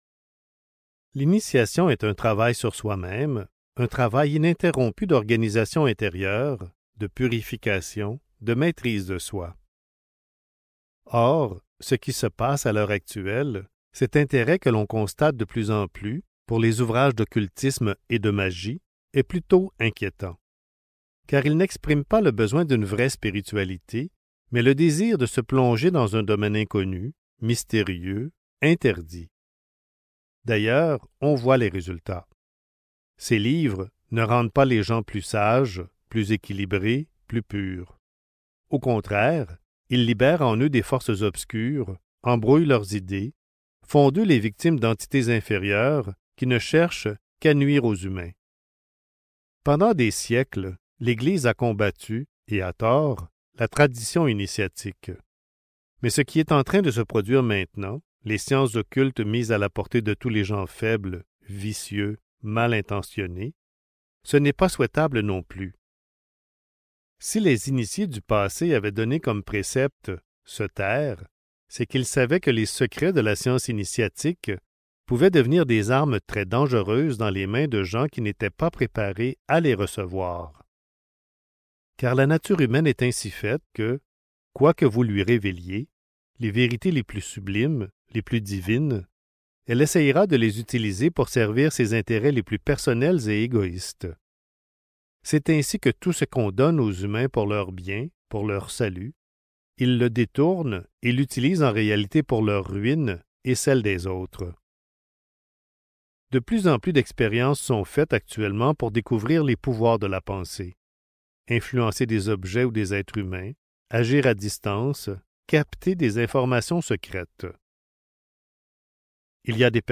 Le livre de la magie divine (Livre audio | CD MP3) | Omraam Mikhaël Aïvanhov